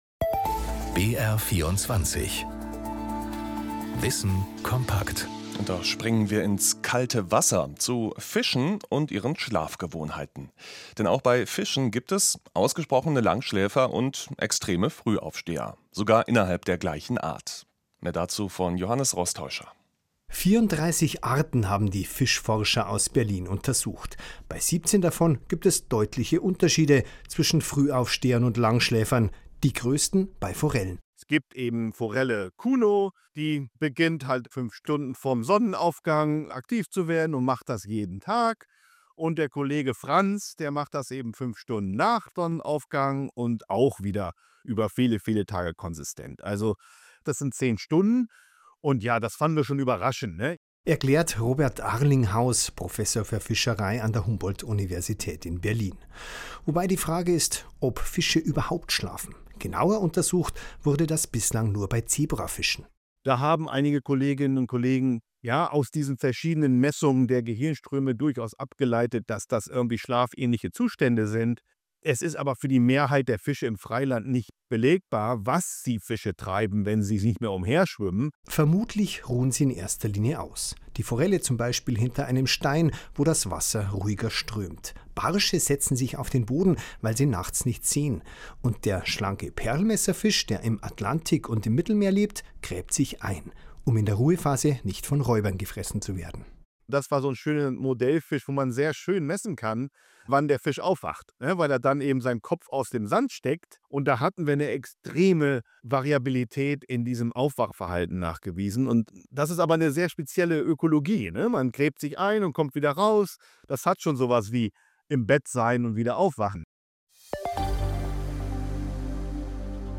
Radio-Interview,
WK_Schlafen_Fische_mit_Anmod.MP3